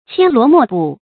牵萝莫补 qiān luó mò bǔ 成语解释 谓无法弥补。